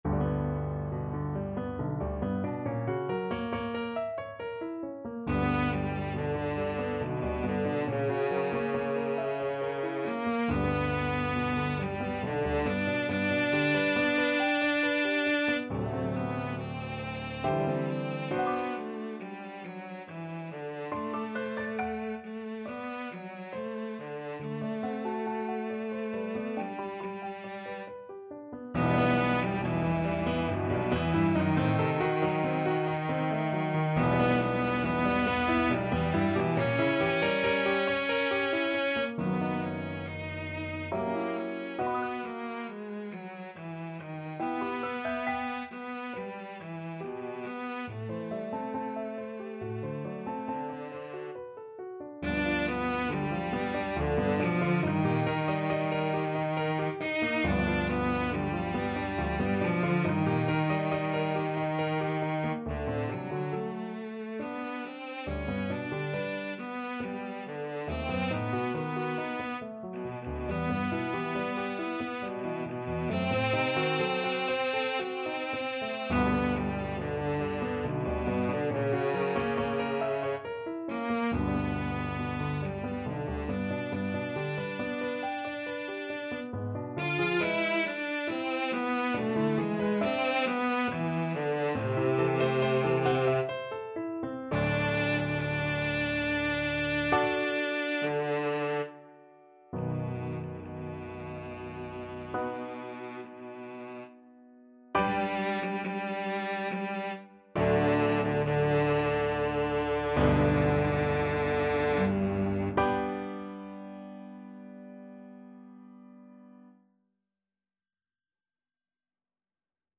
Cello
G major (Sounding Pitch) (View more G major Music for Cello )
3/4 (View more 3/4 Music)
~ = 69 Large, soutenu
Classical (View more Classical Cello Music)